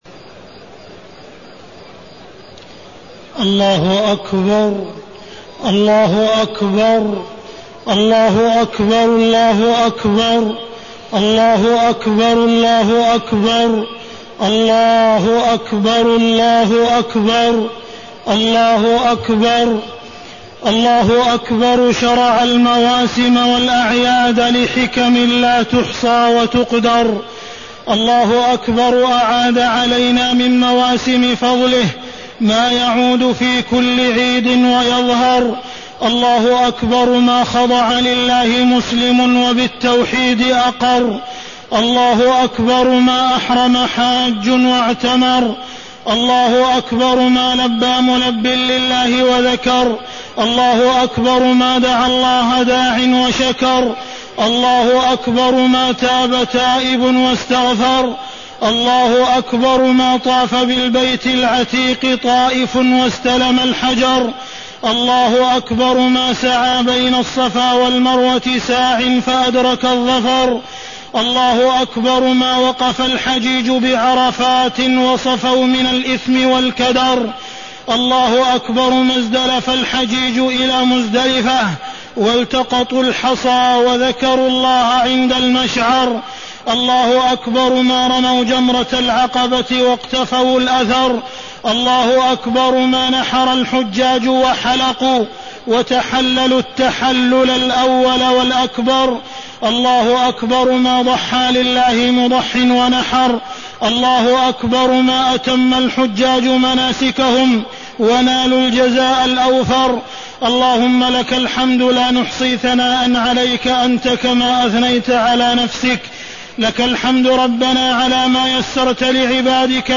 خطبة عيد الأضحى-شعيرة الأضاحي - الموقع الرسمي لرئاسة الشؤون الدينية بالمسجد النبوي والمسجد الحرام
تاريخ النشر ١٠ ذو الحجة ١٤٢٤ هـ المكان: المسجد الحرام الشيخ: معالي الشيخ أ.د. عبدالرحمن بن عبدالعزيز السديس معالي الشيخ أ.د. عبدالرحمن بن عبدالعزيز السديس خطبة عيد الأضحى-شعيرة الأضاحي